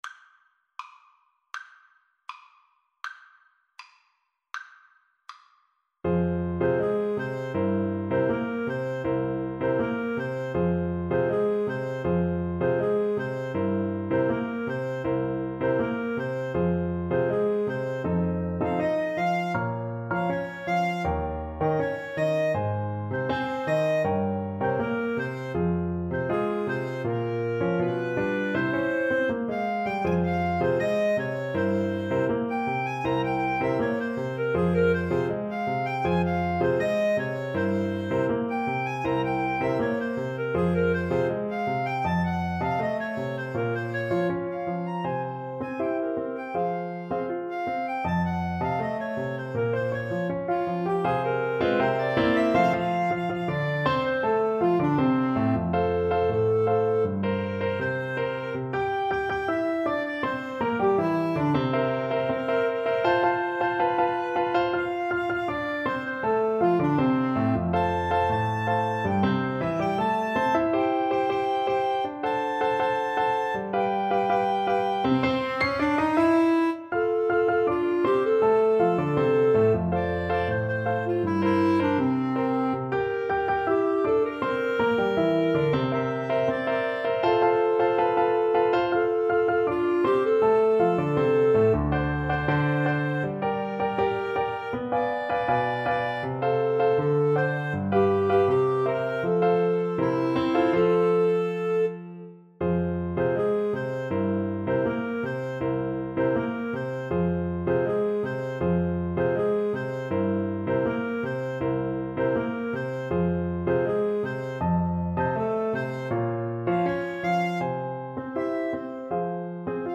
FluteClarinetPiano
A beautiful tango melody in an A-B-A form.
2/4 (View more 2/4 Music)
Grazioso =80
World (View more World Flute-Clarinet Duet Music)